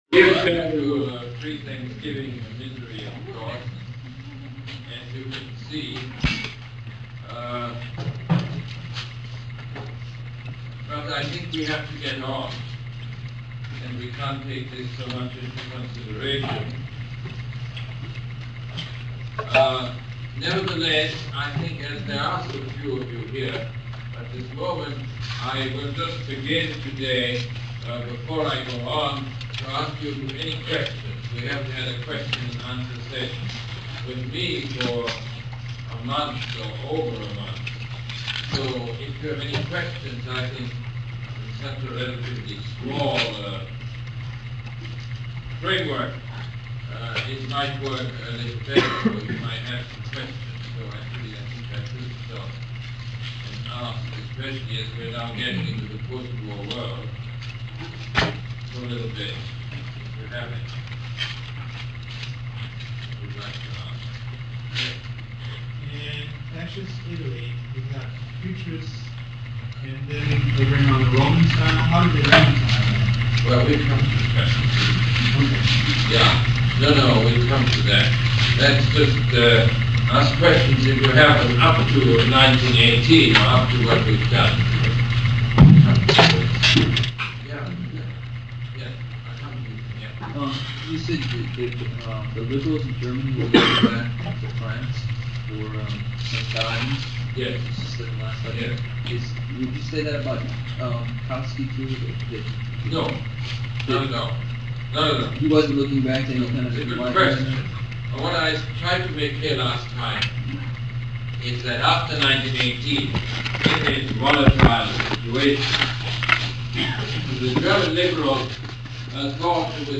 Lecture #22 - November 21, 1979